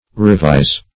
Revise \Re*vise"\, n.